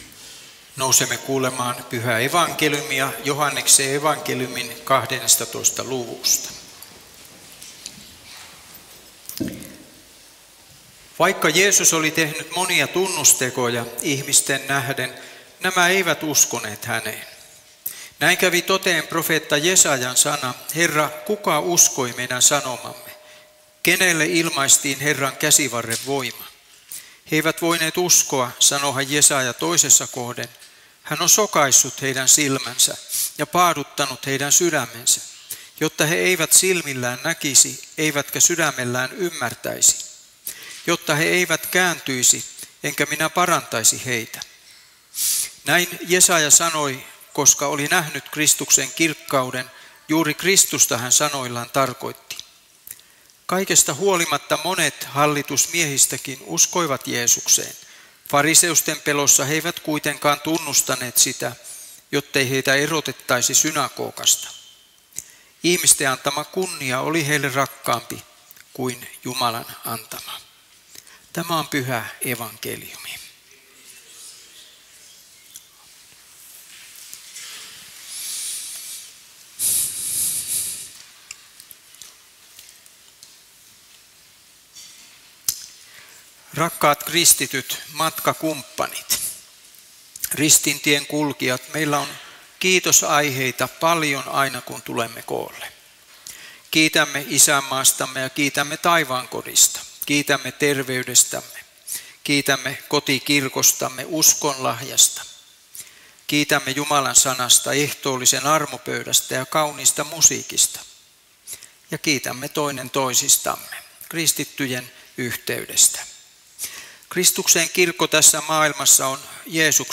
saarna Halsualla 3. paastonajan sunnuntaina Tekstinä Joh. 12:37–43